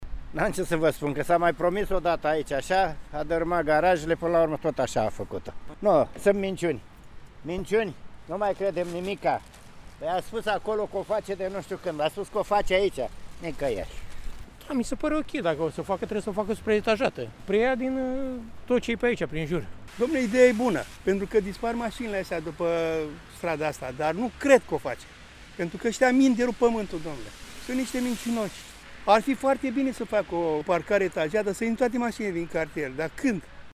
Constănțenii spun că nu se mai așteaptă la ridicarea parcărilor: